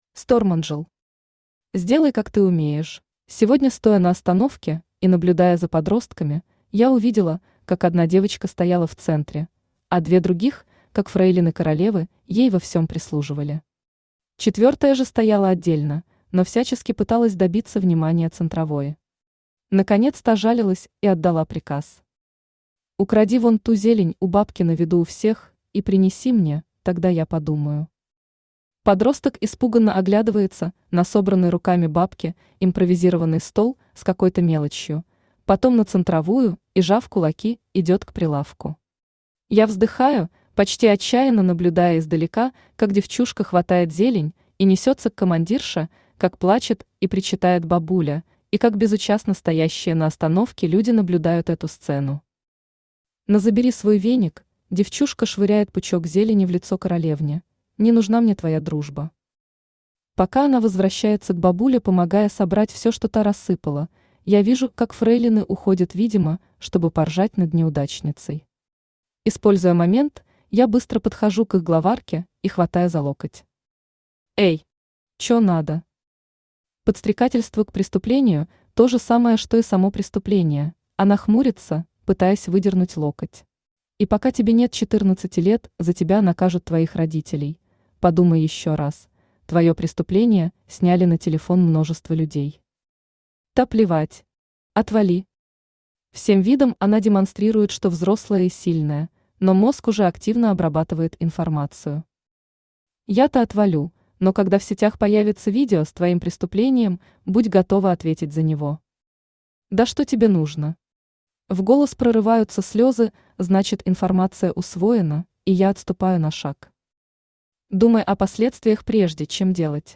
Аудиокнига Сделай как ты умеешь | Библиотека аудиокниг
Aудиокнига Сделай как ты умеешь Автор Stormangel Читает аудиокнигу Авточтец ЛитРес.